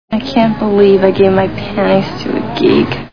Sixteen Candles Movie Sound Bites